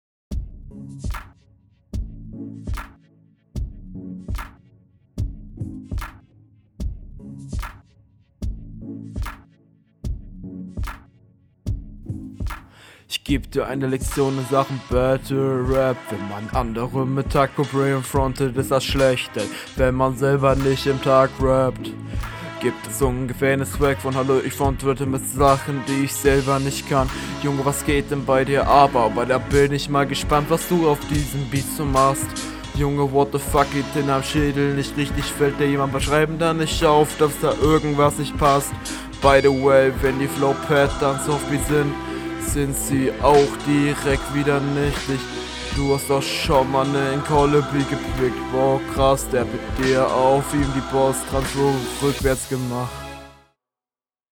Flow: Leider hat mich dein Flow gar nicht angesprochen, das Problem ist einfach dass du …